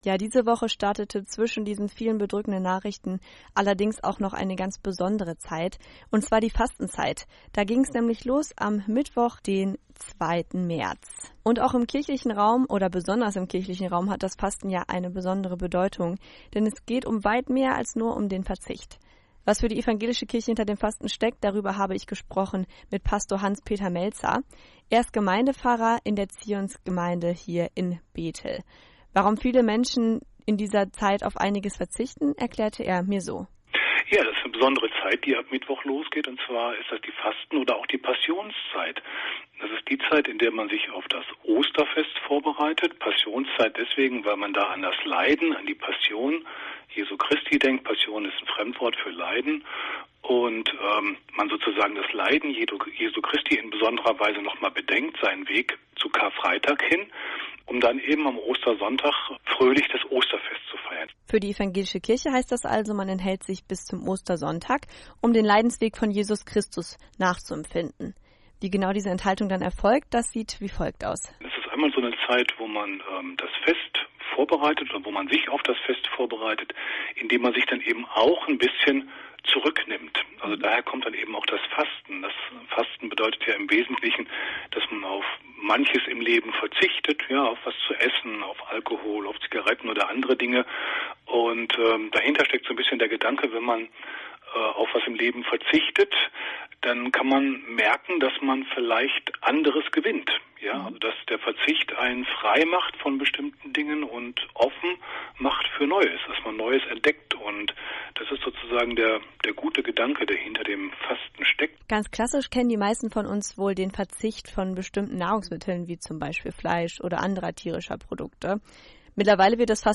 im Gepsäch